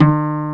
EPM AKUSTI.4.wav